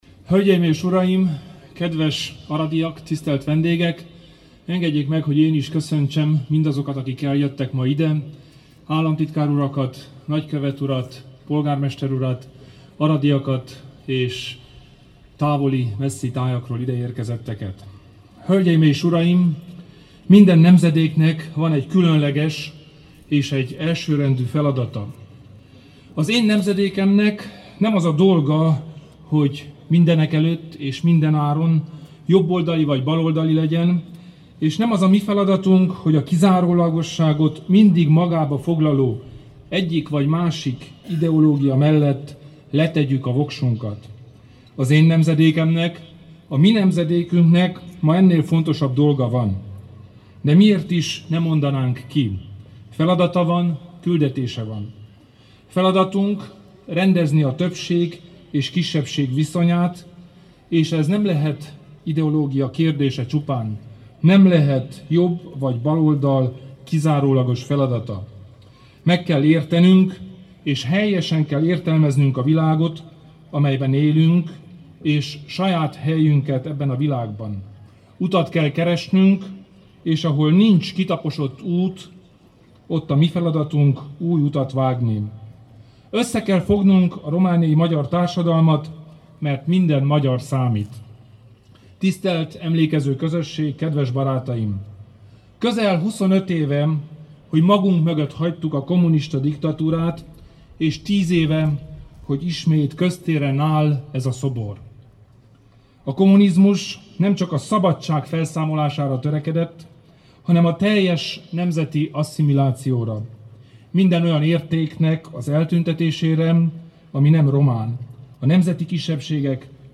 A mi nemzedékünknek feladata van – Kelemen Hunor beszéde október 6-án [AUDIÓ]